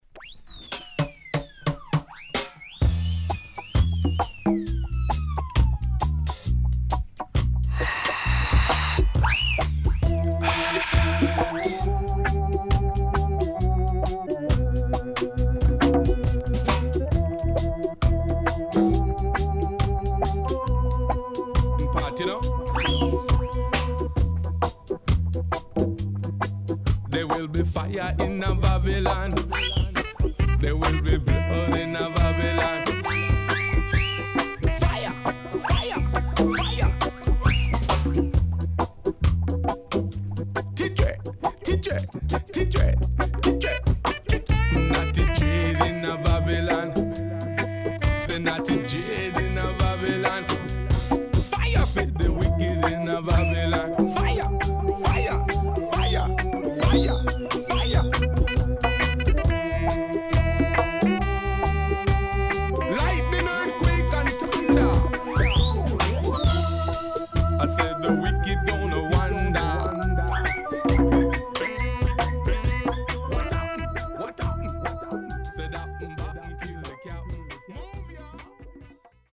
76年Very Rare UK Deep Roots